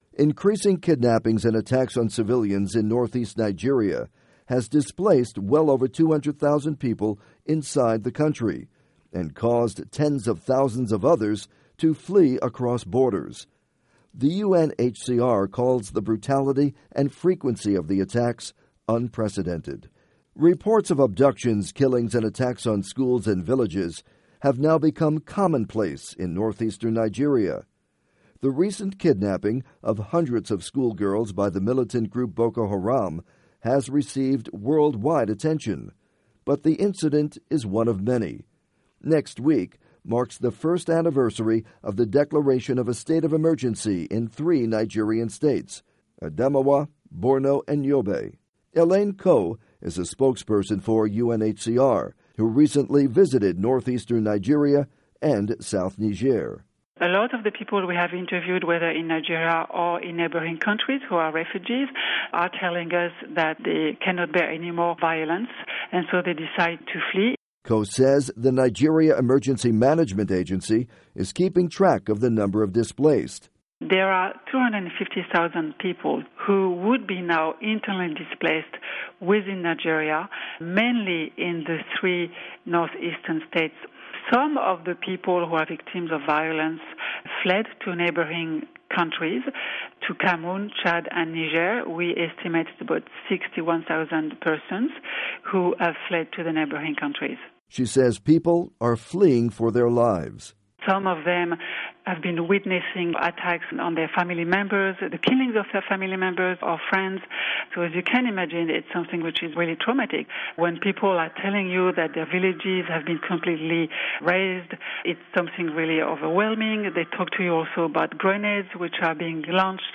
report on Nigeria displaced and refugees